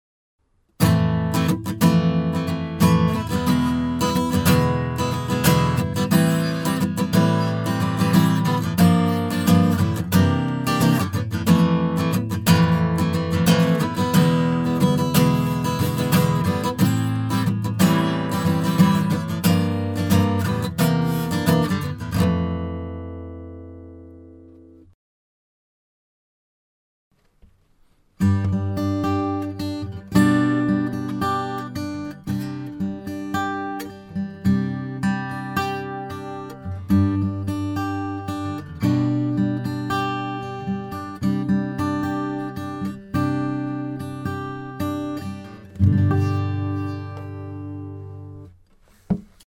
試聴用のオーディオは、前半にストローク、後半にアルペジオが入っています。
ストロークの音はOptoの暴れた感じが減って聴きやすくなりました。アルペジオも穏やかで聴きやすくなった感じがします。